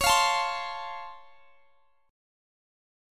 Listen to C#+M7 strummed